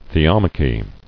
[the·om·a·chy]